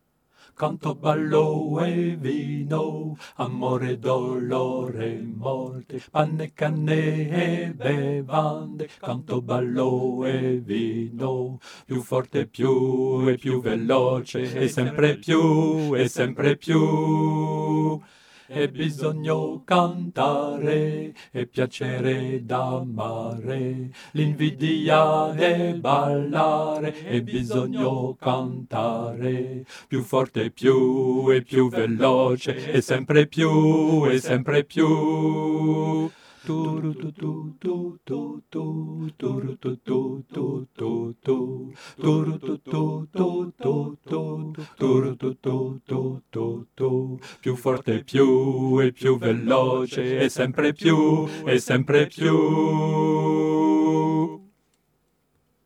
Alto
Cette chanson doit rester légère,